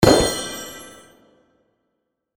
magic_explosion.9de33226.mp3